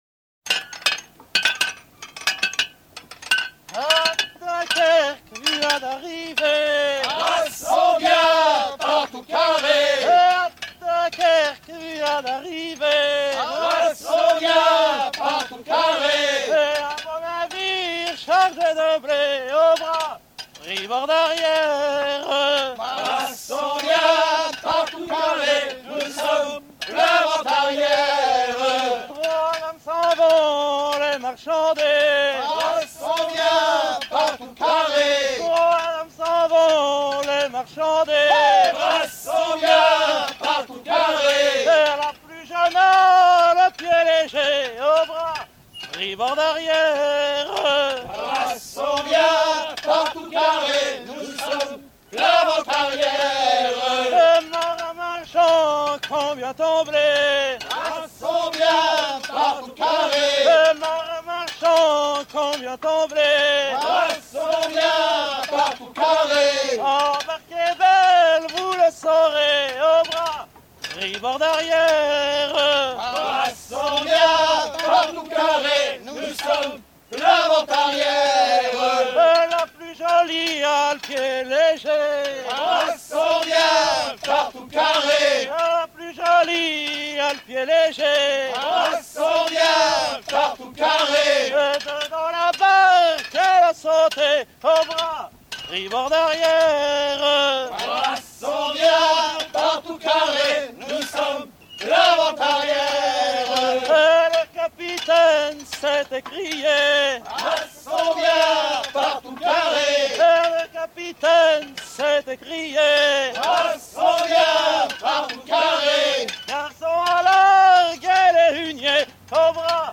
à virer au guindeau
Genre laisse